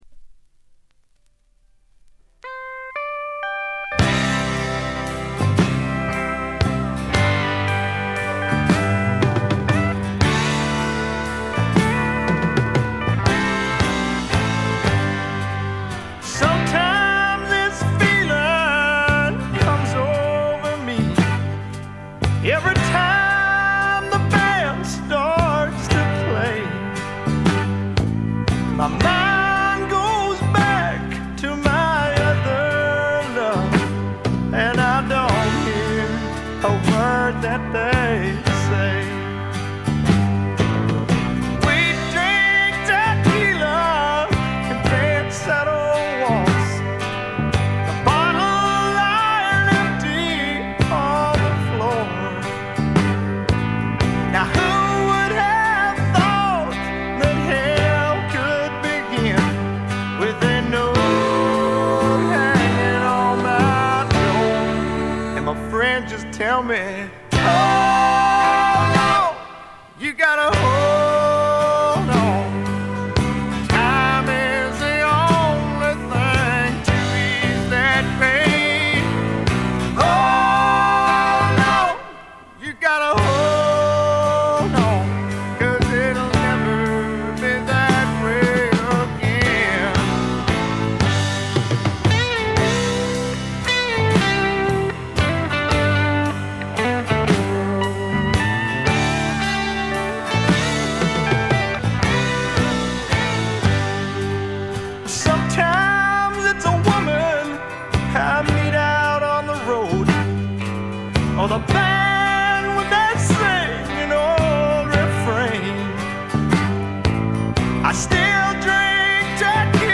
ホーム > レコード：米国 スワンプ
部分試聴ですが、微細なノイズ感のみ。
試聴曲は現品からの取り込み音源です。